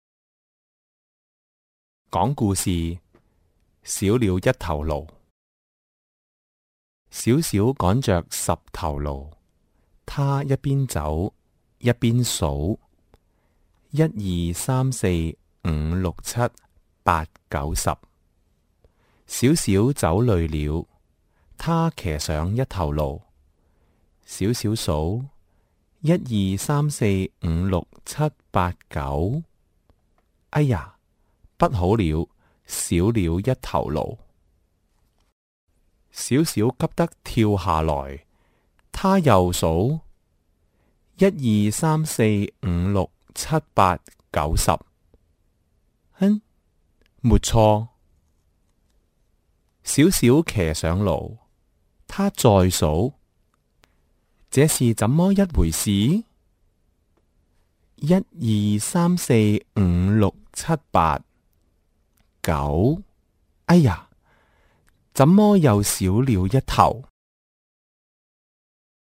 9. 廣東話錄音: Cantonese Story Recording):
B1_T1_storyA.mp3